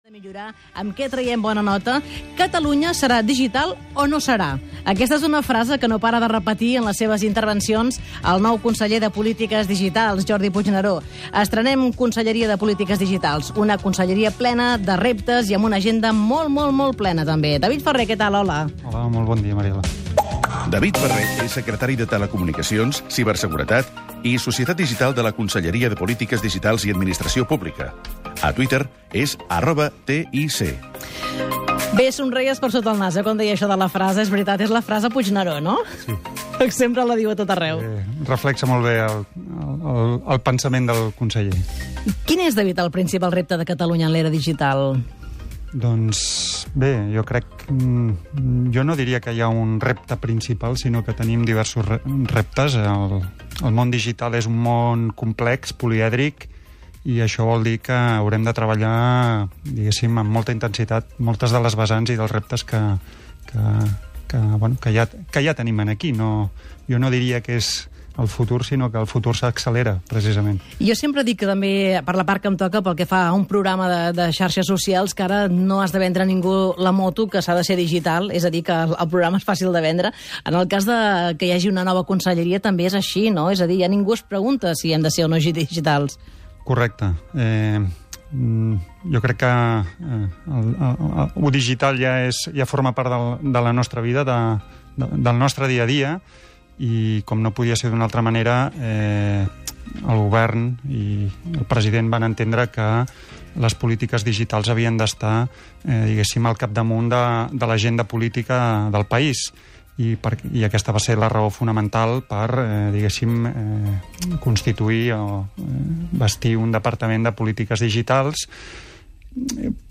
Entrevista David Ferre a Cat Radio, secretari de Telecomunicacions, Ciberseguretat i Societat Digital de la Generalitat.